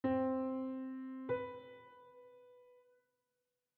Major 7th
C-Major-Seventh-Interval-S1.wav